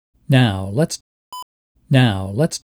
They usually correspond when your voice is loud, so not extraneous (e.g. computer) noise from the in the room.
They are on the raw version, so not caused by pitch shifting, (but will become more obvious by shifting down) Sounds like clicking within the microphone.